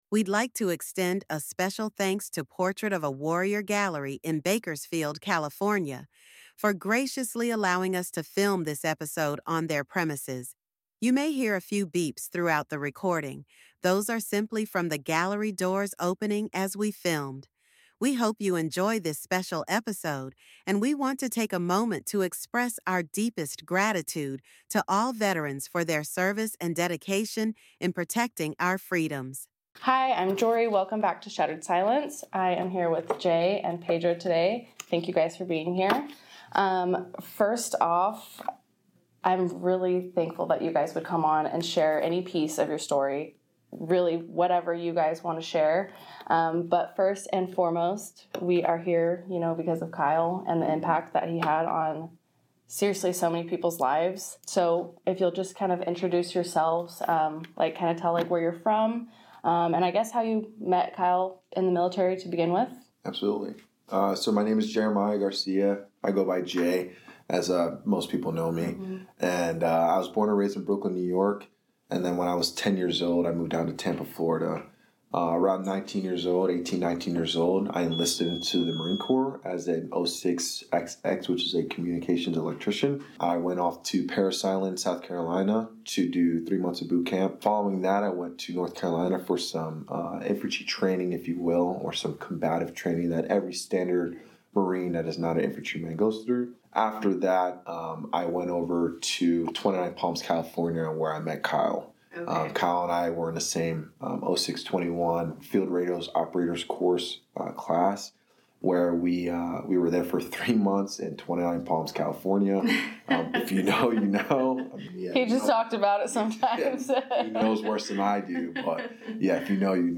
Tune in for a powerful conversation that sheds light on the complexities of grief and the path to healing after loss.